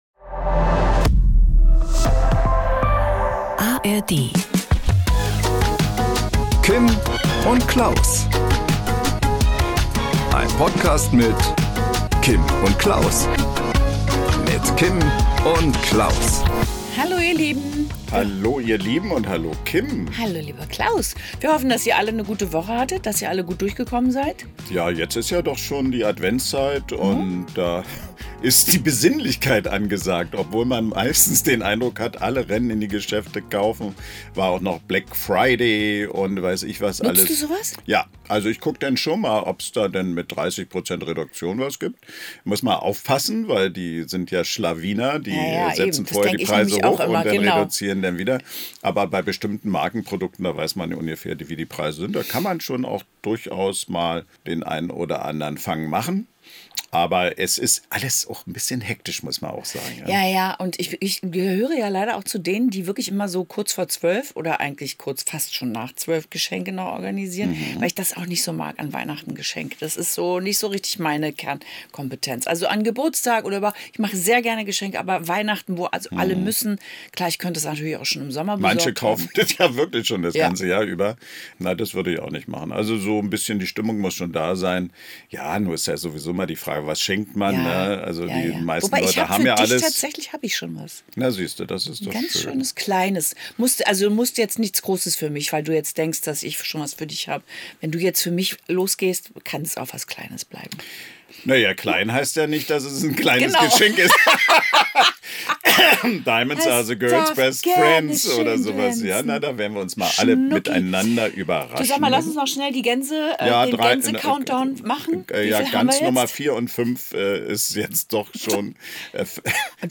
Am Promi-Telefon: Entertainerin Gayle Tufts, famous für ihr wonderfulles Denglisch.